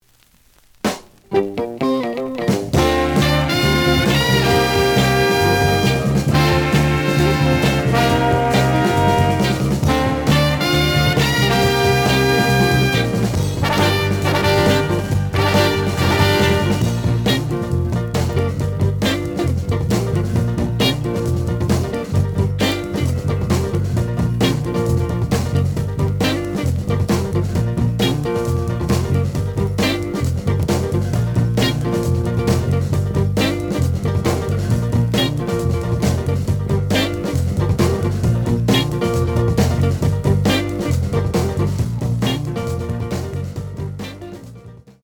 The audio sample is recorded from the actual item.
●Format: 7 inch
●Genre: Funk, 70's Funk
Slight edge warp.